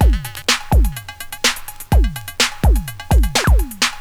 1 channel
rhythm1.wav